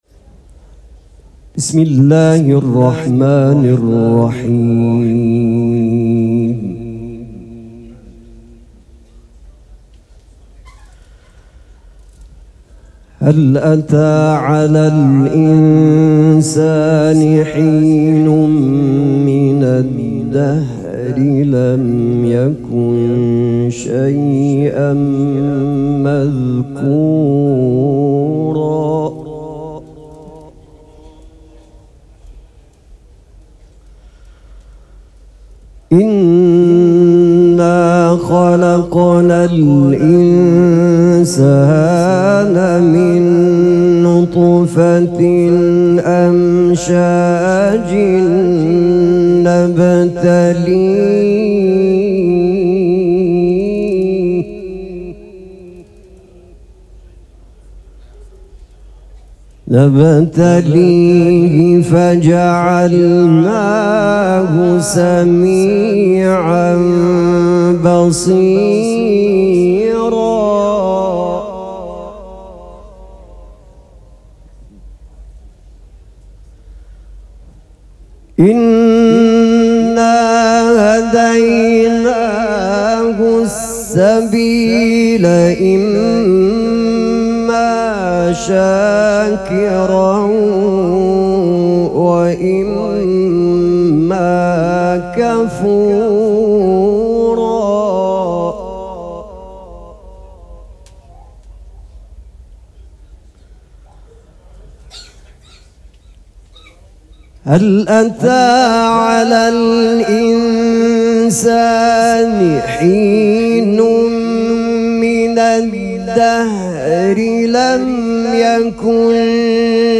قرائت قرآن